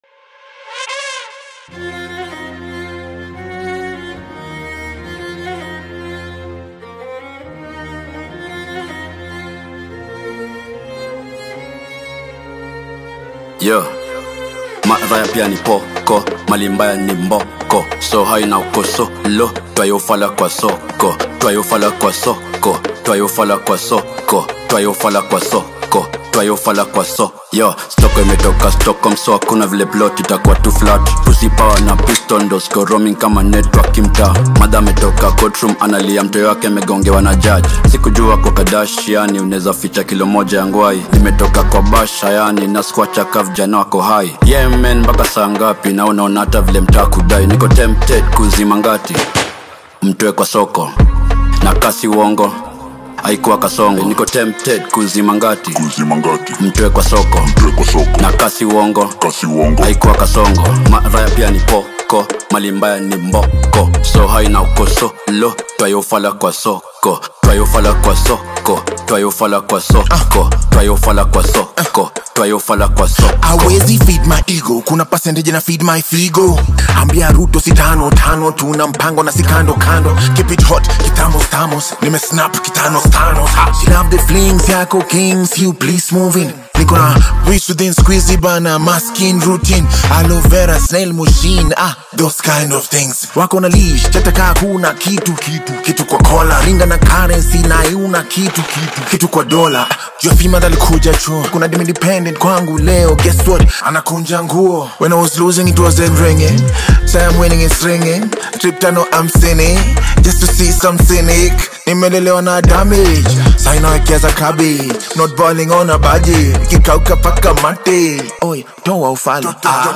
” In this energetic song